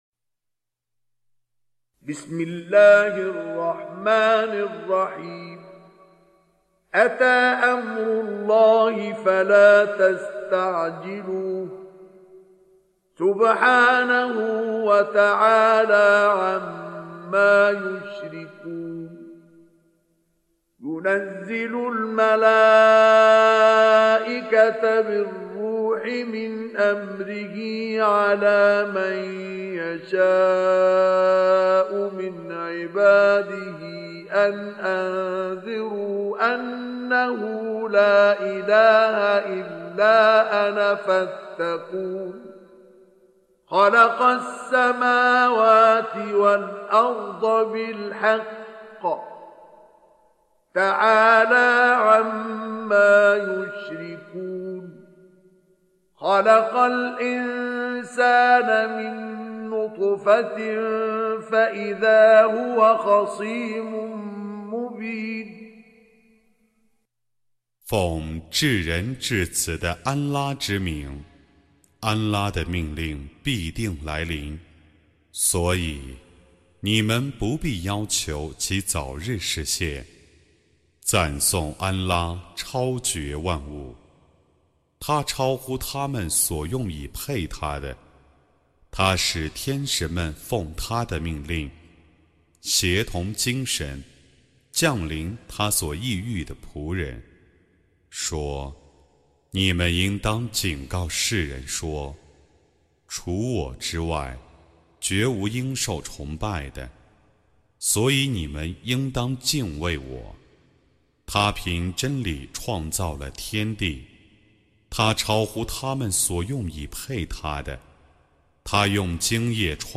Surah Sequence تتابع السورة Download Surah حمّل السورة Reciting Mutarjamah Translation Audio for 16. Surah An-Nahl سورة النحل N.B *Surah Includes Al-Basmalah Reciters Sequents تتابع التلاوات Reciters Repeats تكرار التلاوات